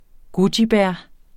Udtale [ ˈgodjiˌbæɐ̯ ]